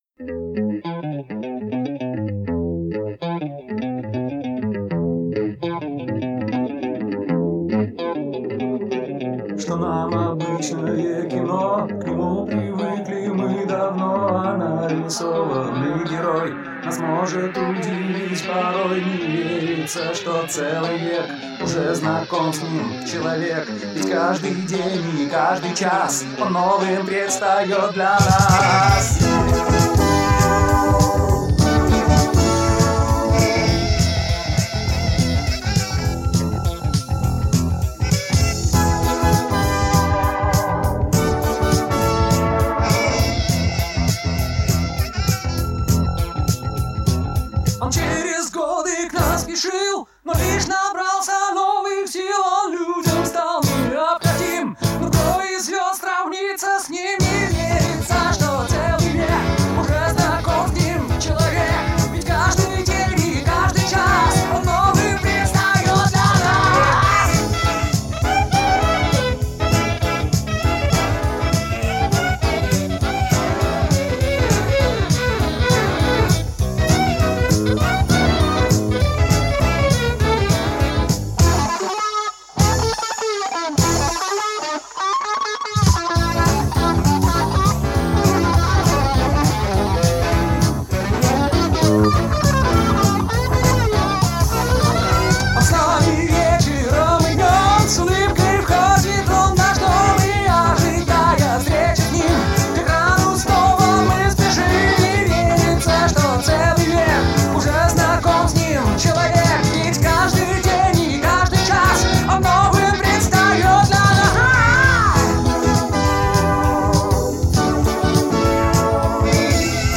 ударные